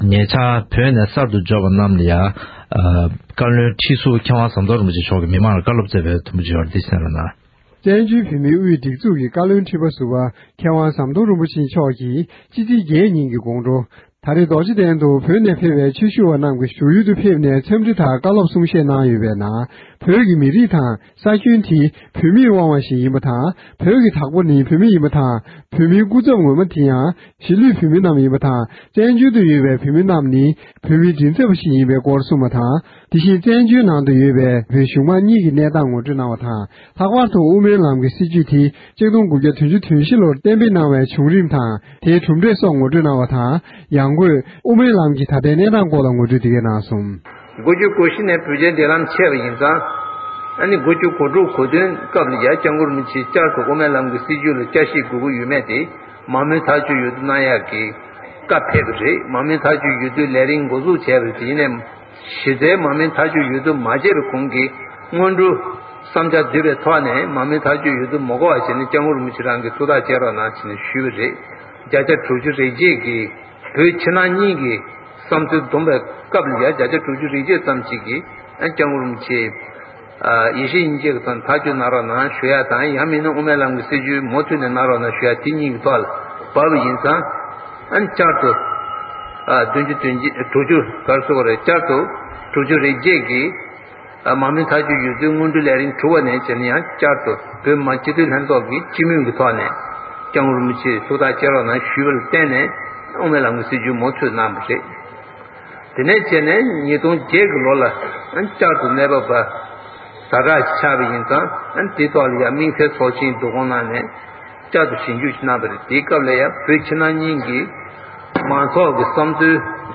བཀའ་བློན་ཁྲི་ཟུར་ཟམ་གདོང་རིན་པོ་ཆེ་མཆོག་གིས་བོད་ནས་ཕེབས་པ་རྣམས་ལ་བཀའ་སློབ་སྩལ་བ།